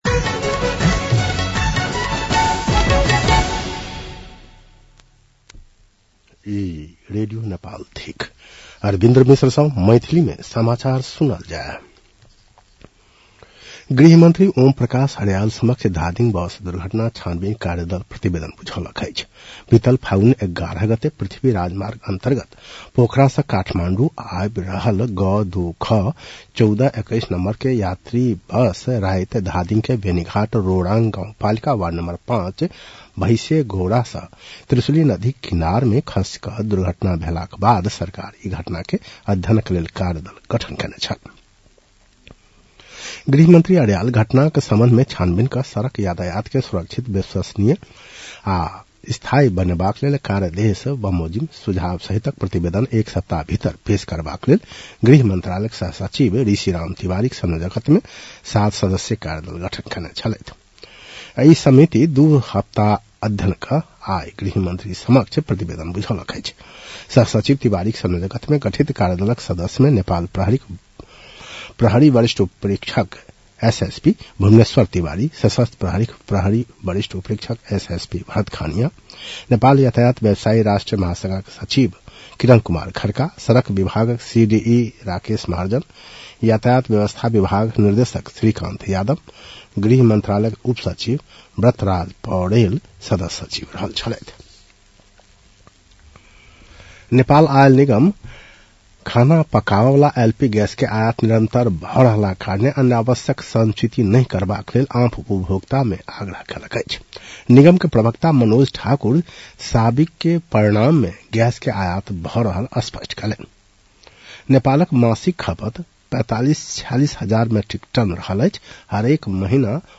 मैथिली भाषामा समाचार : २९ फागुन , २०८२
Maithali-news-11-29.mp3